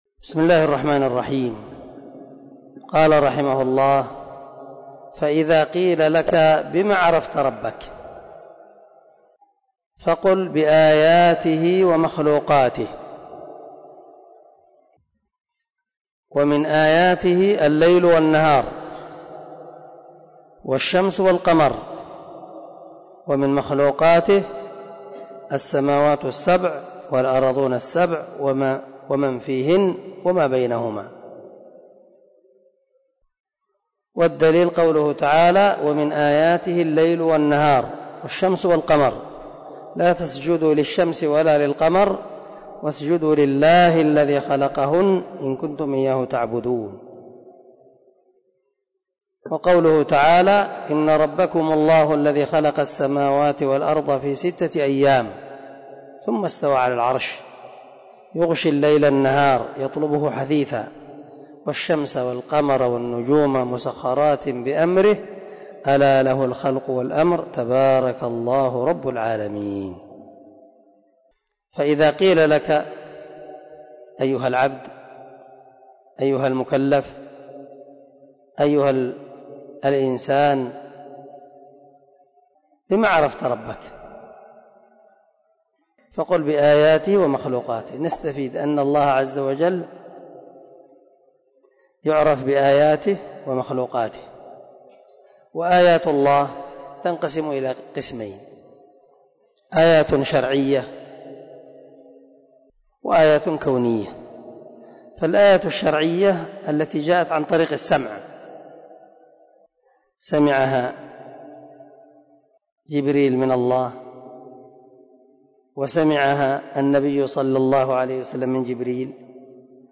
🔊 الدرس 9 من شرح الأصول الثلاثة
الدرس-9-تابع-الأصل-الأول.mp3